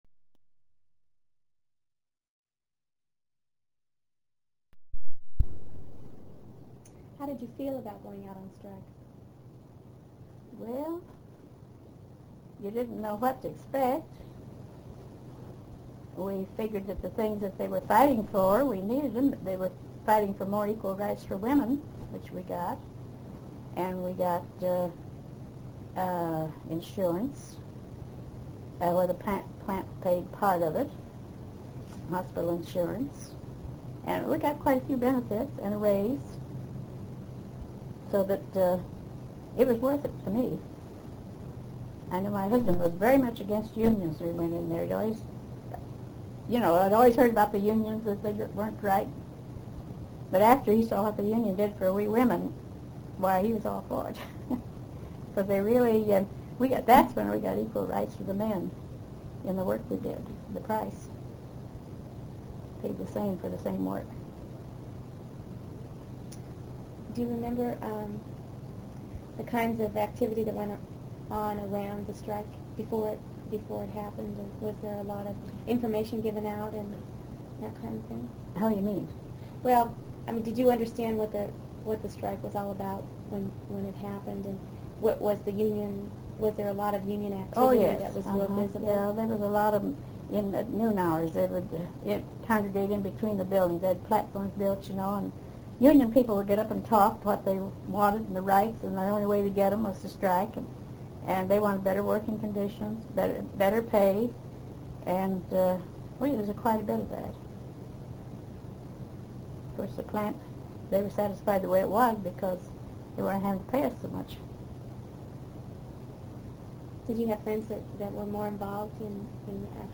INTERVIEW DESCRIPTION